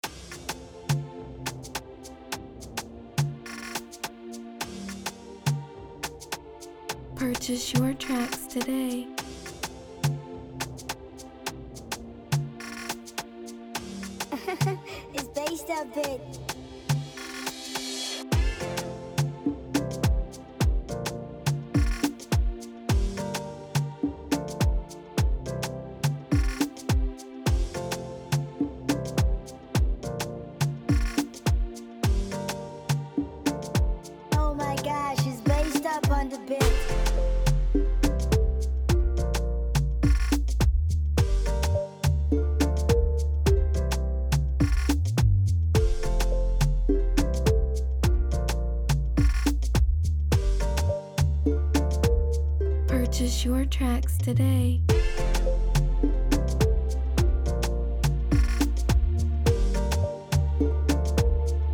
In the realm of Afrobeat, the instrumental track